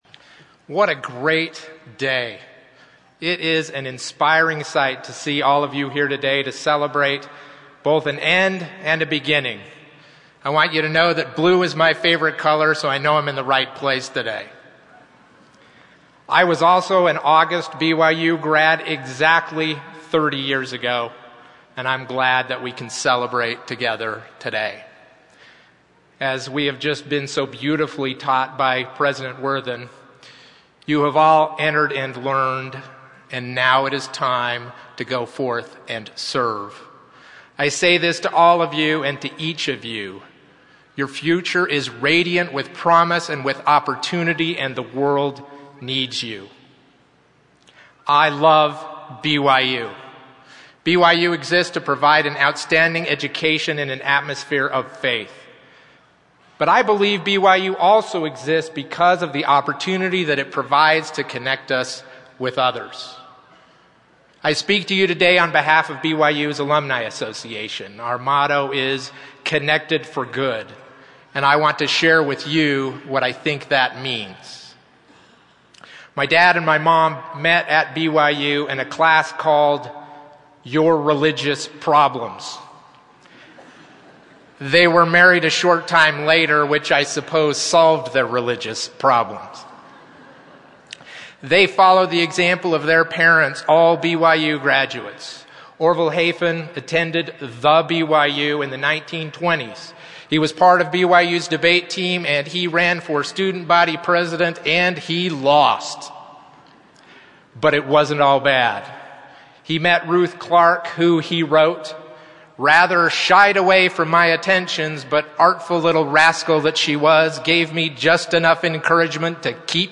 delivered his commencement addresses on August 2018